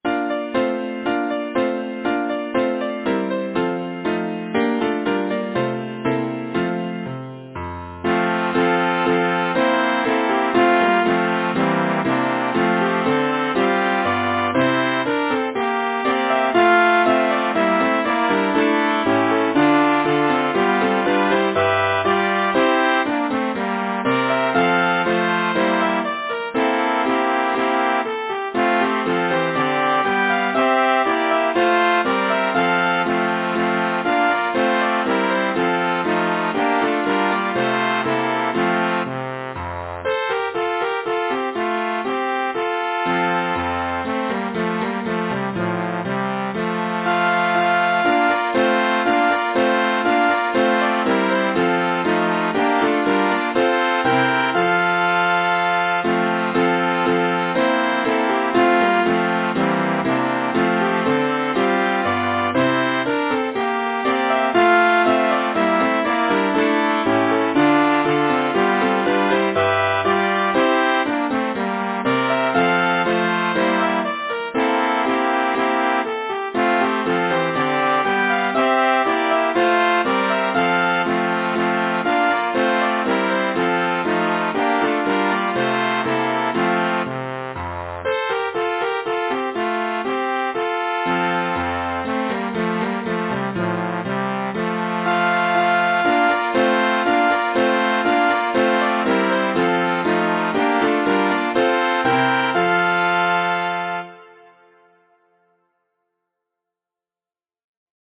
Title: Madrigal Composer: George Balch Nevin Lyricist: Thomas Weelkes Number of voices: 4vv Voicing: SATB Genre: Secular, Partsong
Language: English Instruments: Piano